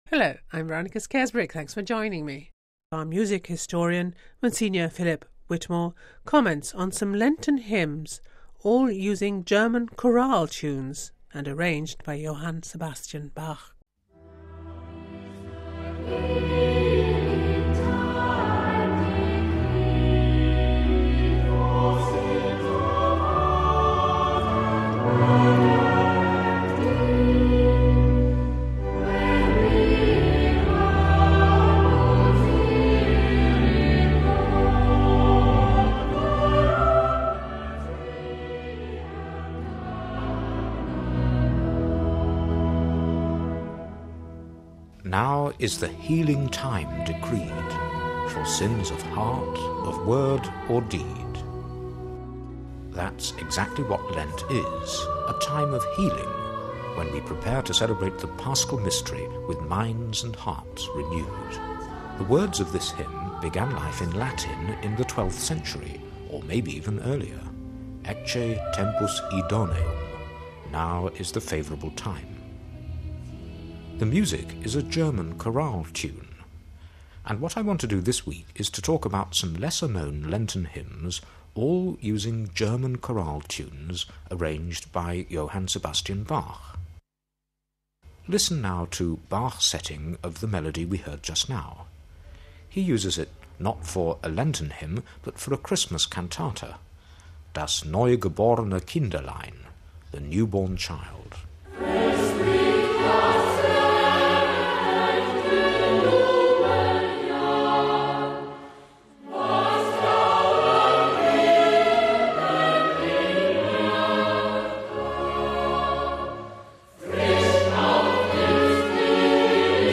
Lenten hymns , all using German chorale tunes arranged by Johann Sebastian Bach.